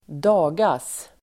Ladda ner uttalet
Uttal: [²d'a:gas]